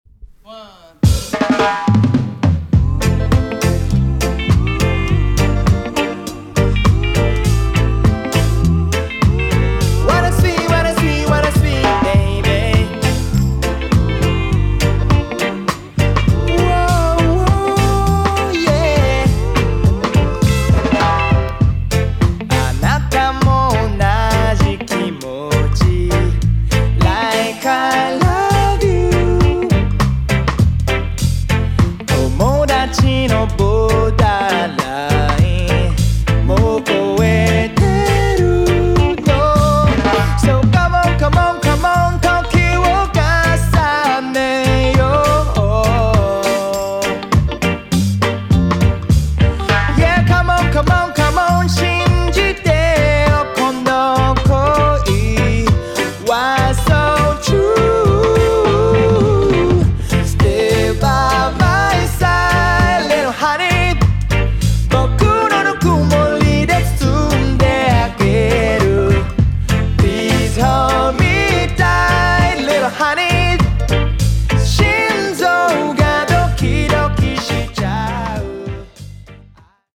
キャッチーでポップなメロディで世界中のレゲエ・ファンの心をつかんだ
2016年に神奈川で結成されたルーツ・ロック・レゲエ・バンド。
多世代メンバーによる厚みのあるサウンドと、ジャマイカで培った体験を核にしたスピリチュアルな音楽性が特徴。